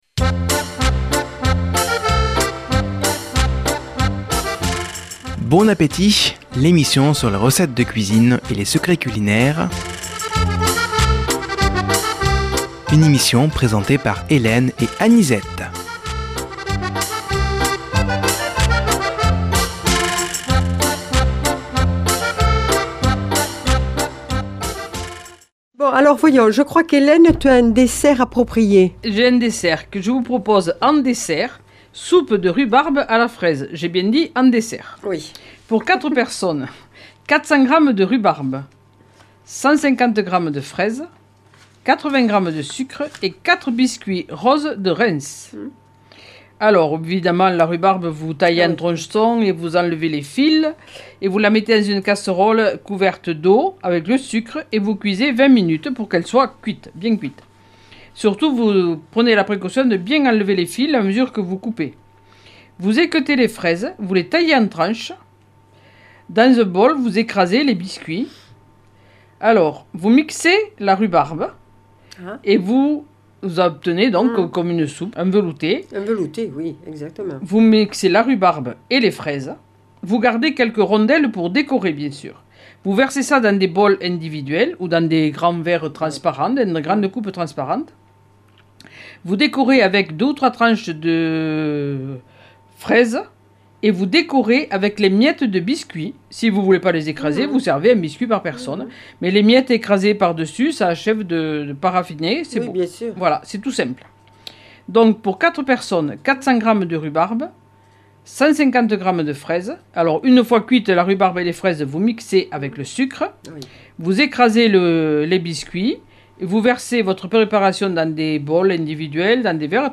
Présentatrices